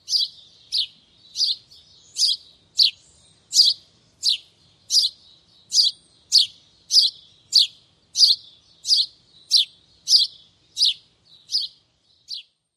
家麻雀鸟鸣声